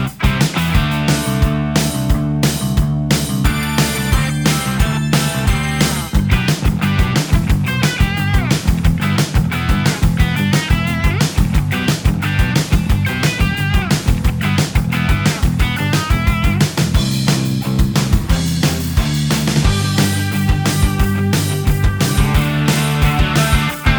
Minus All Guitars Punk 3:45 Buy £1.50